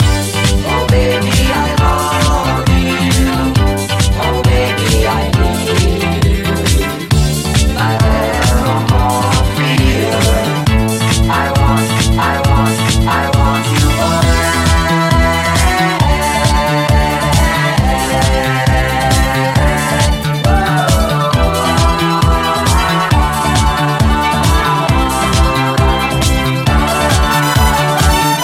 italo disco